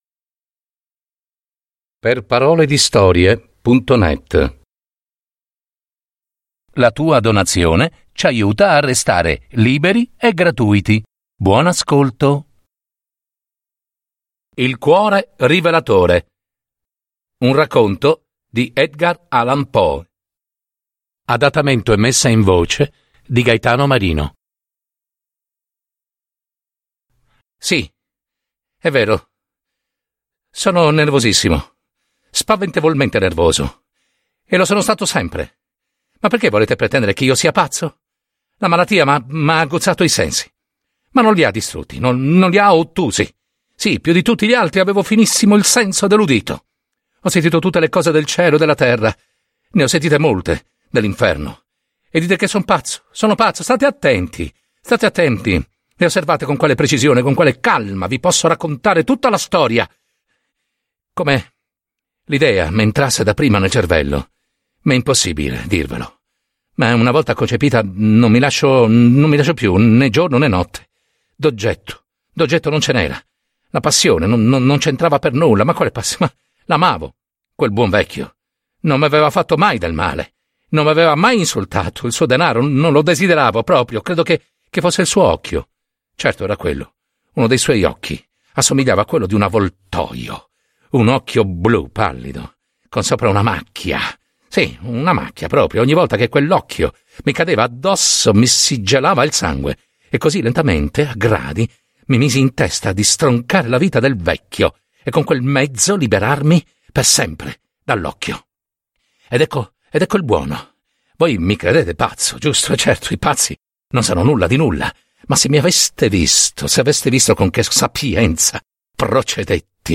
Adattamento e messa in voce